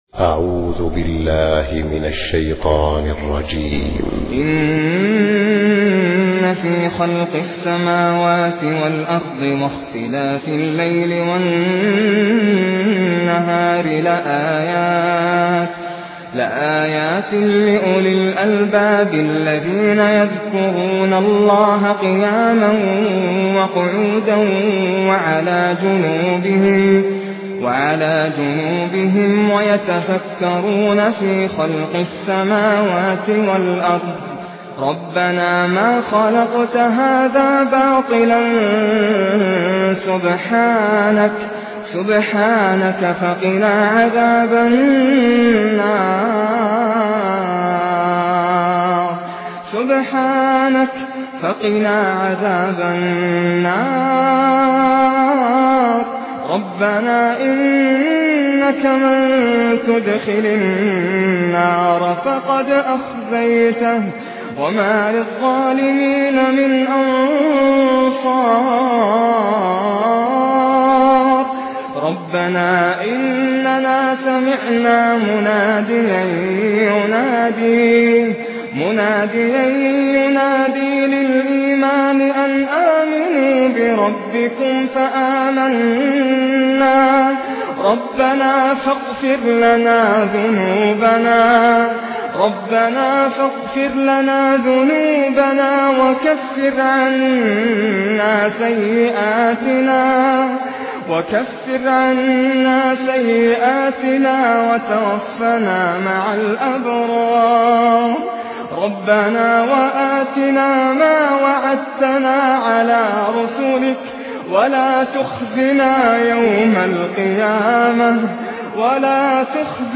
Surah Al Imran, verses 190-195, reciter Nasser Al-Qatami
Humble, distinctive recitations